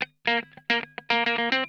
PICKIN 4.wav